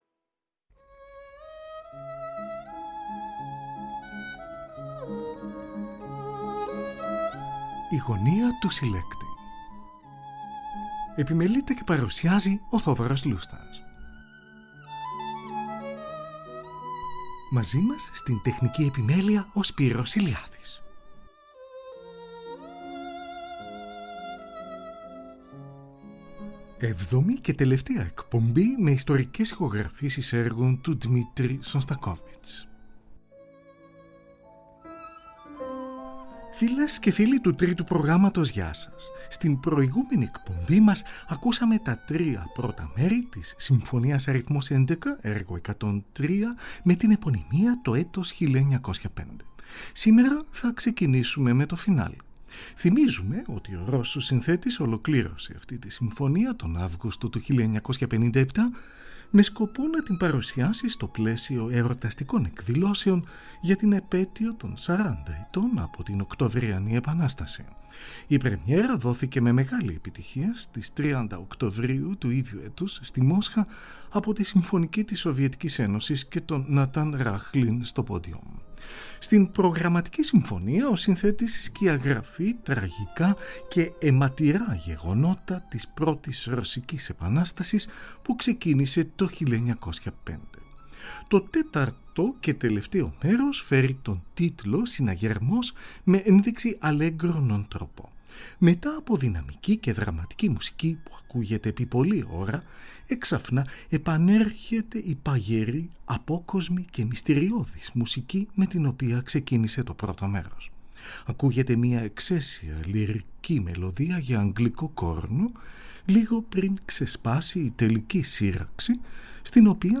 Τρίο για πιάνο, βιολί και βιολοντσέλο, Αρ.2, έργο 67.
από ηχογράφηση στην Πράγα
Συμφωνια